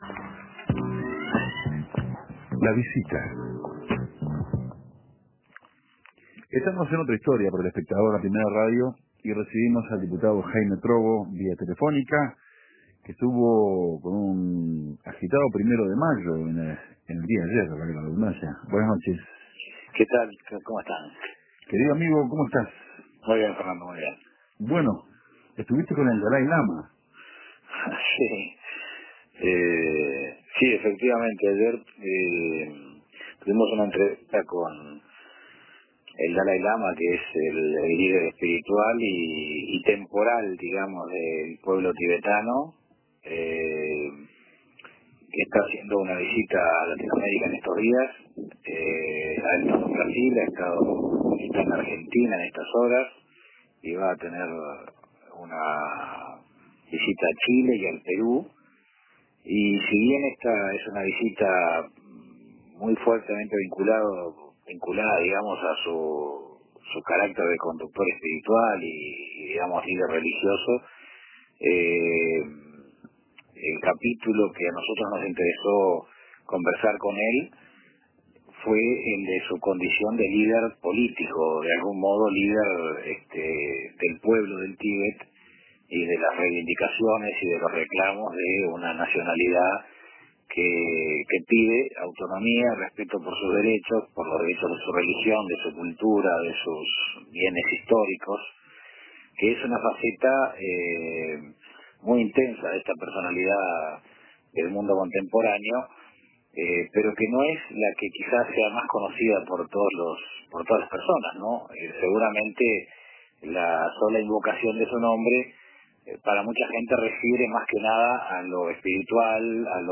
Entrevista a Jaime Trobo